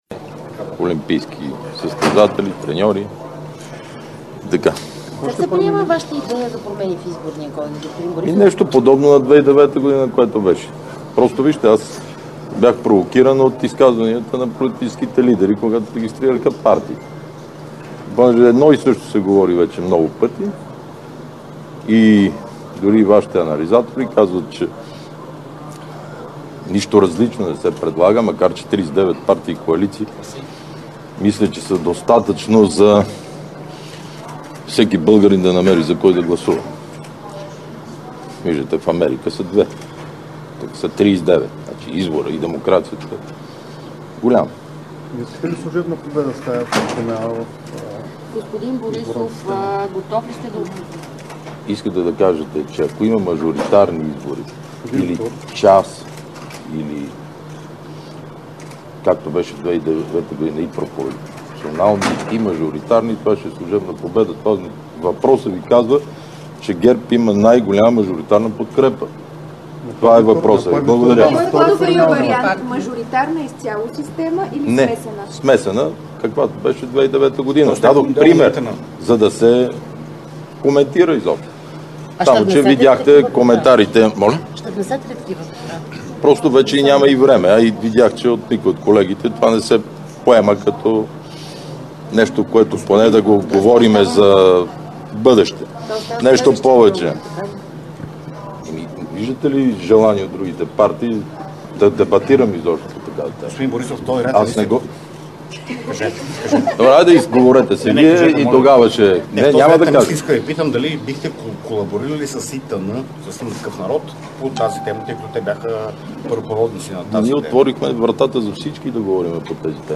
9.35 - Заседание на Пленума на Висшия съдебен съвет.
Директно от мястото на събитието